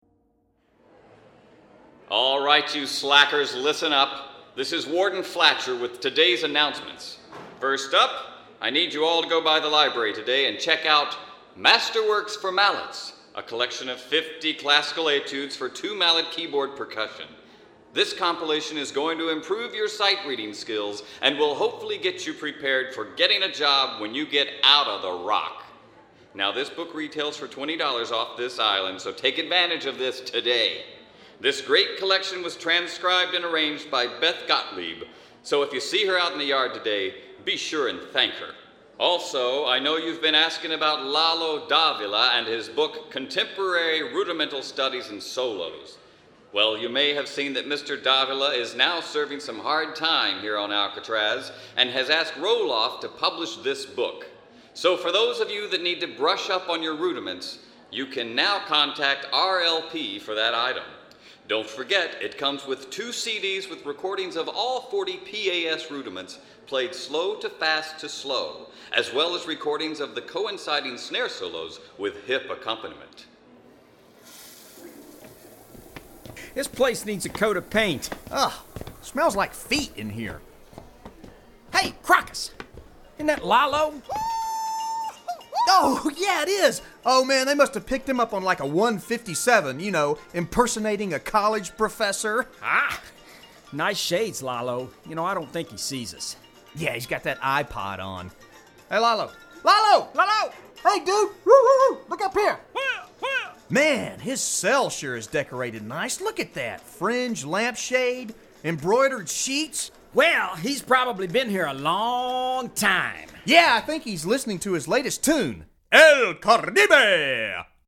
Voicing: Marimba Collection